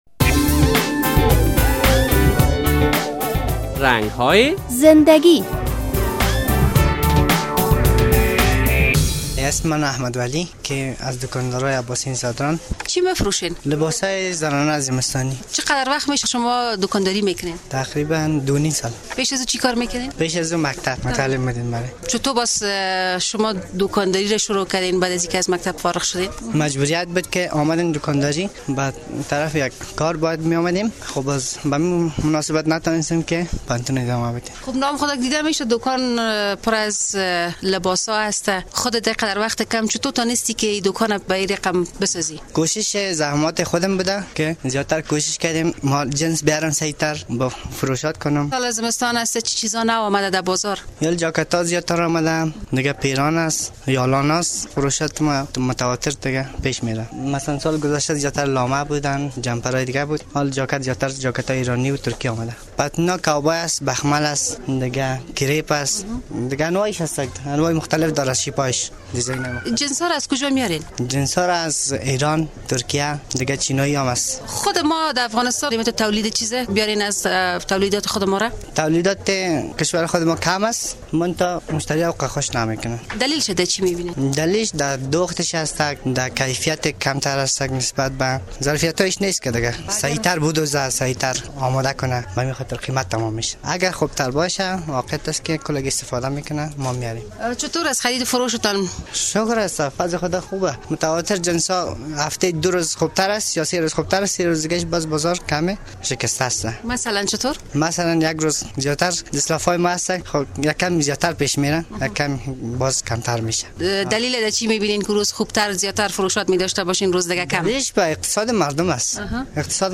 یک تن از دکانداران شهر کابل مصاحبه شده است.